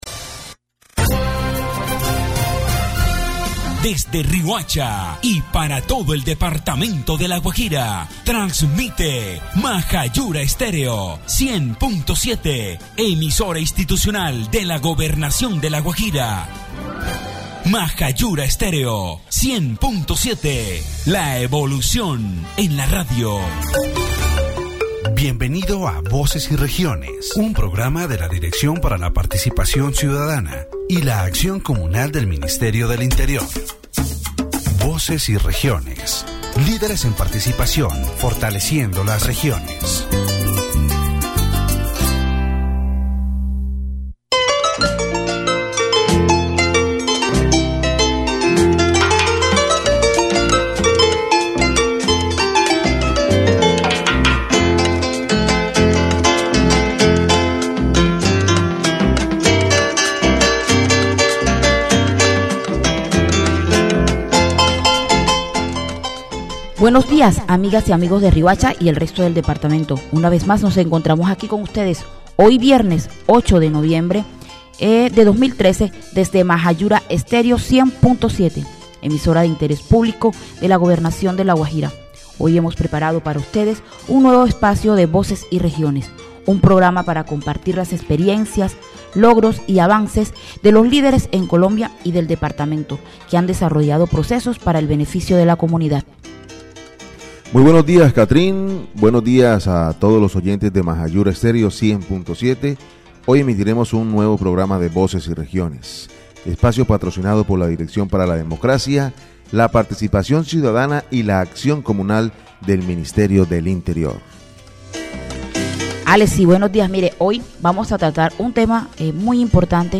The Voces y Regiones program, broadcast on Maja Yura Estéreo 100.7, discusses youth participation in politics in La Guajira, Colombia.